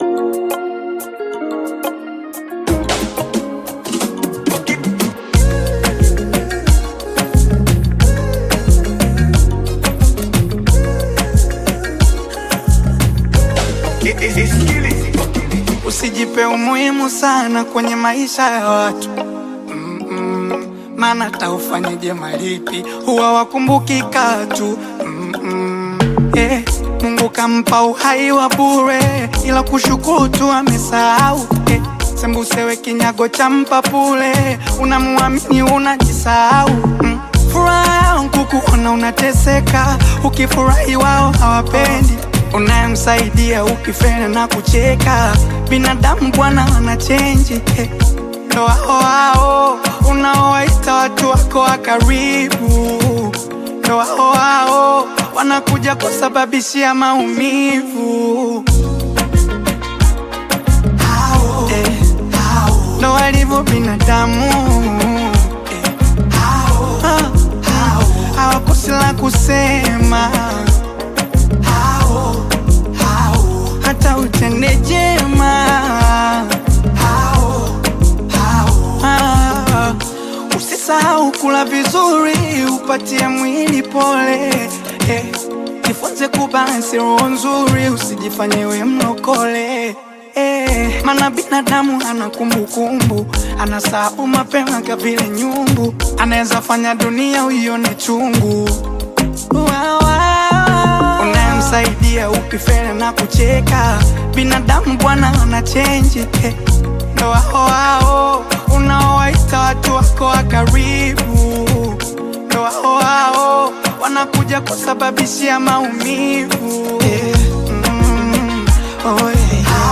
Bongo Flava
Bongo Flava song